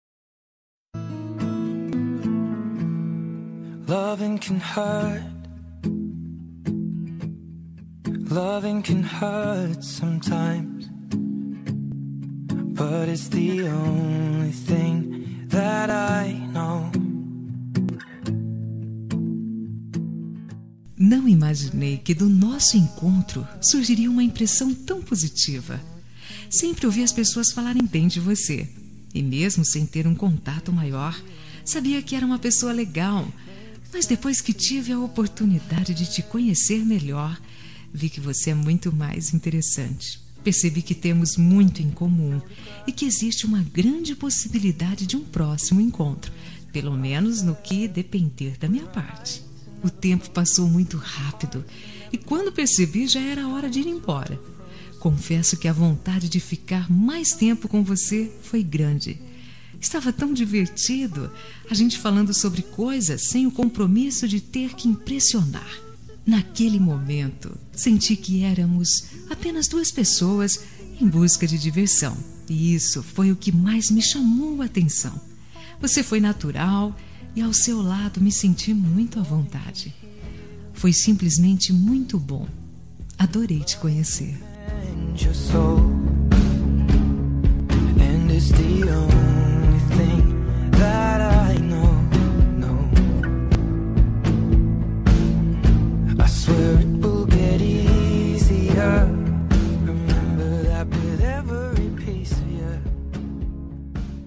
Telemensagem Momentos Especiais – Voz Feminina – Cód: 201816 – Adorei te Conhecer